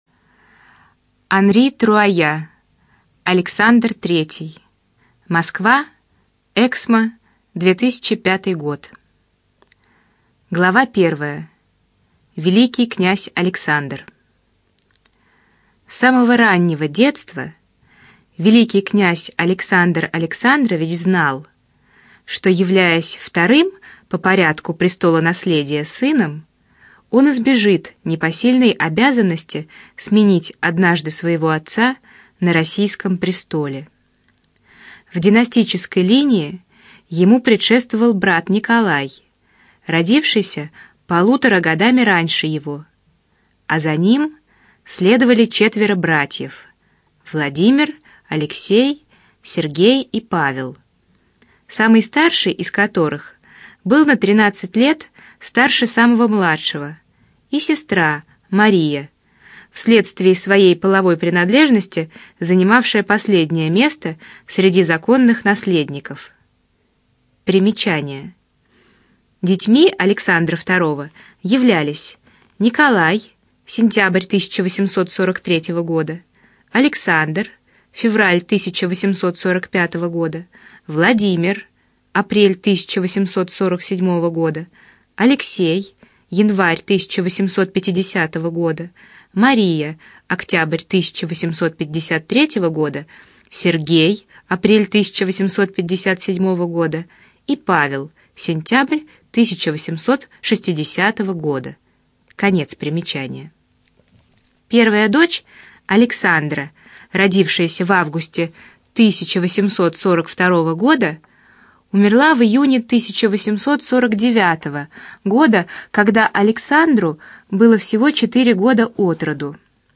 ЖанрИсторическая проза, Биографии и мемуары
Студия звукозаписиЛогосвос